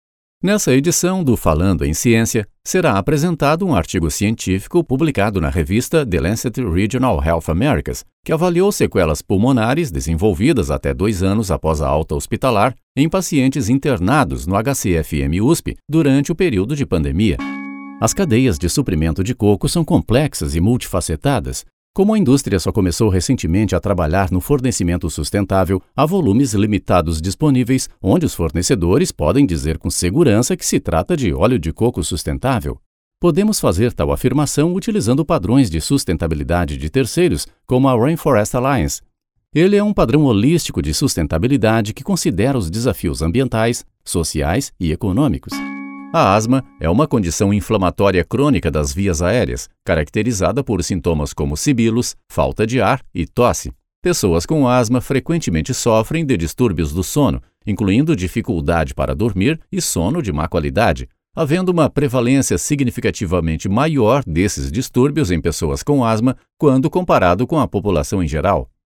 Narración médica
Mi voz es profunda, amigable, natural y conversacional.
Trabajo desde mi propio estudio profesionalmente equipado y con tratamiento acústico.